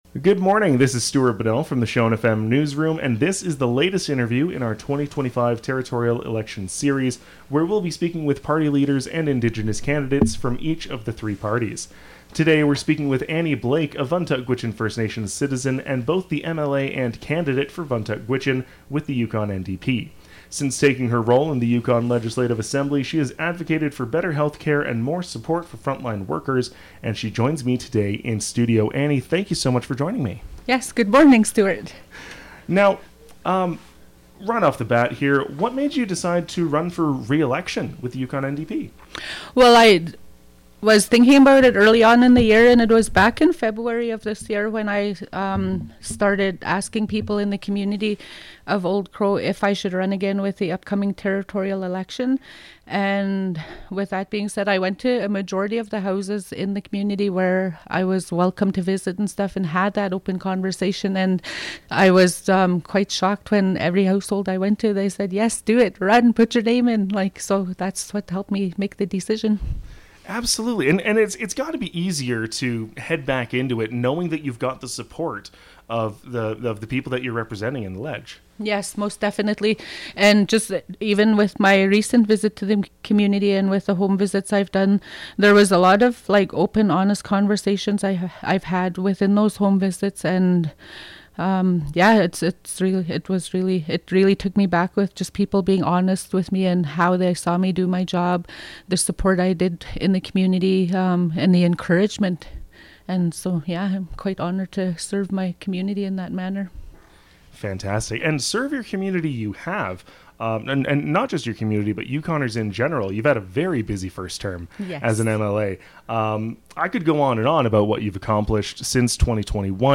2025 Territorial Election Interview Series: Yukon NDP candidate for Vuntut Gwitchin Annie Blake